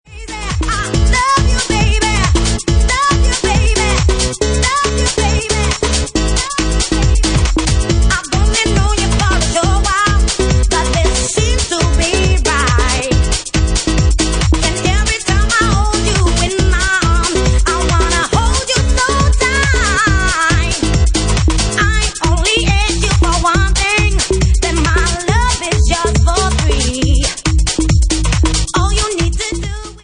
Bassline House at 141 bpm